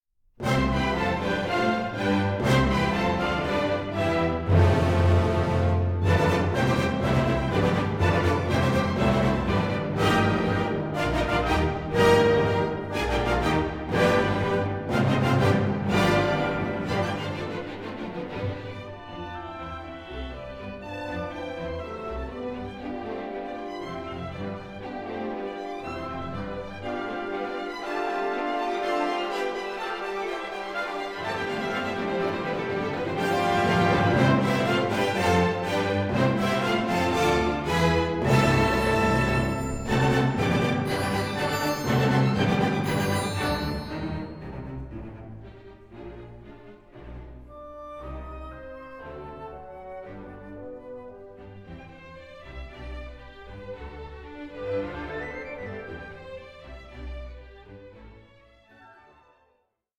Allegro giocoso 6:25